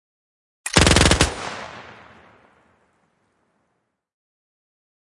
乌兹冲锋枪
描述：模拟UZI声音。
标签： 射击 军事 喷雾 射击 武器 乌兹 射击 冲锋枪
声道立体声